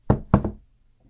Nunavik » snow block of snow igloo brick put down crunch or hit or pat (works at low level)
标签： igloo block brick put down snow crunch
声道立体声